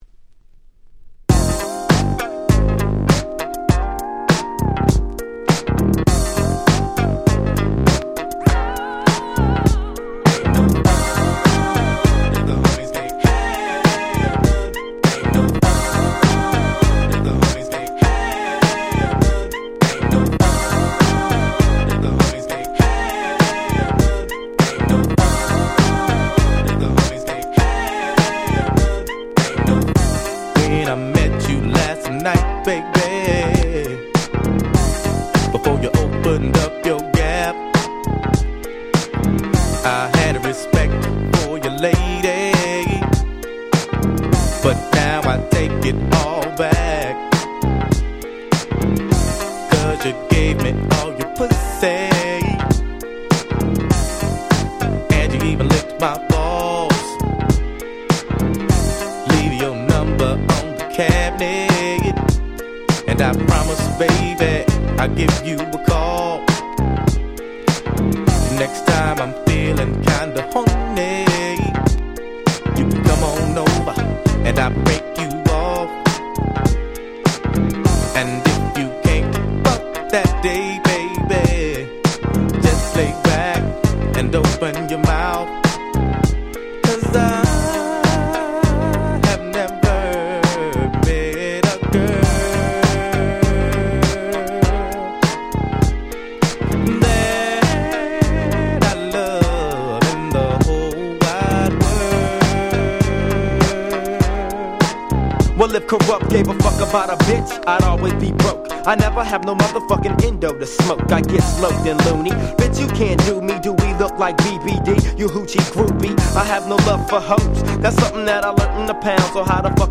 93' West Coast Hip Hop Super Classics !!